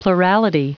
Prononciation du mot plurality en anglais (fichier audio)
Prononciation du mot : plurality